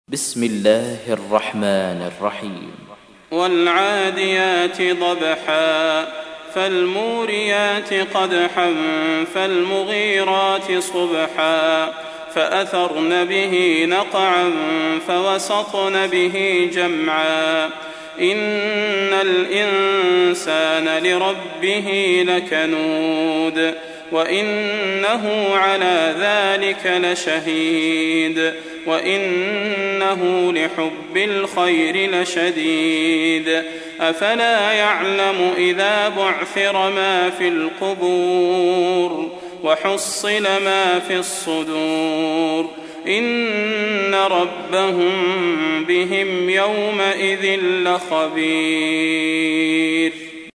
تحميل : 100. سورة العاديات / القارئ صلاح البدير / القرآن الكريم / موقع يا حسين